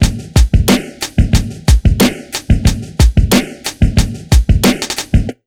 Index of /90_sSampleCDs/USB Soundscan vol.02 - Underground Hip Hop [AKAI] 1CD/Partition A/11-91BEATMIX